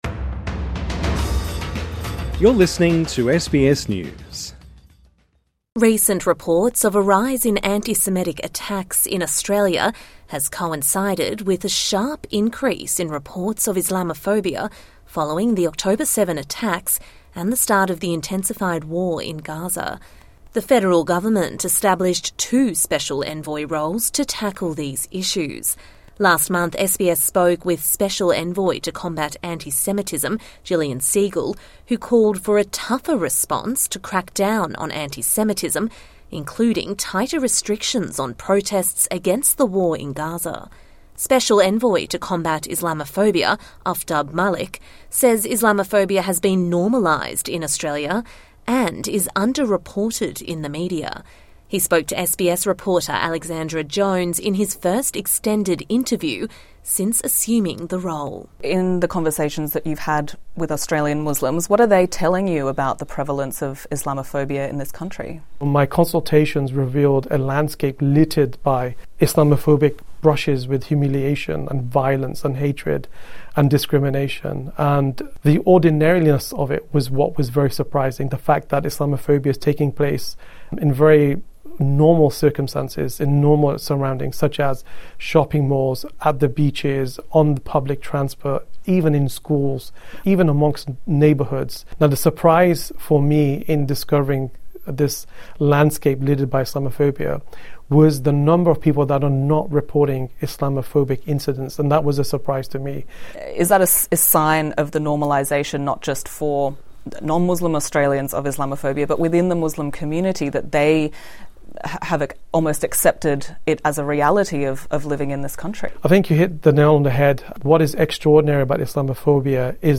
INTERVIEW: Anti-Islamophobia envoy says Islamophobia 'normalised' and 'under-reported' in Australia